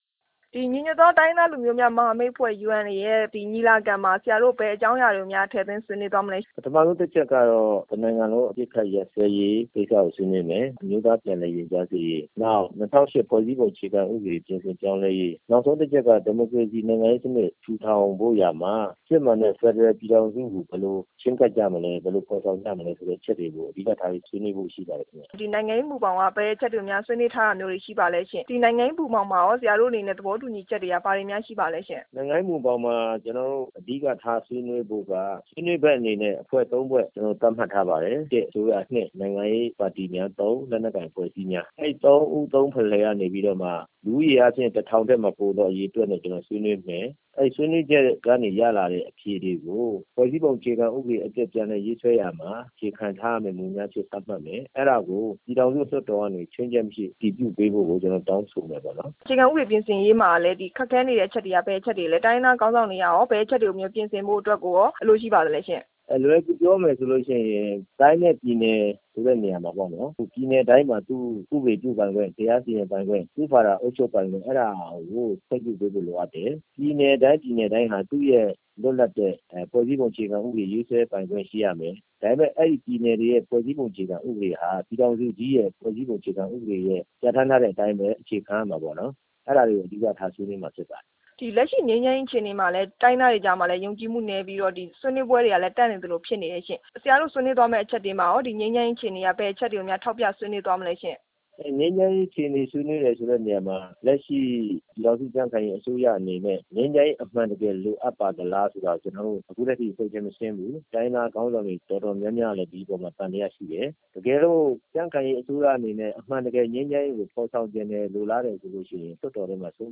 UNA ညီလာခံကျင်းပ မယ့်အကြောင်း မေးမြန်းချက်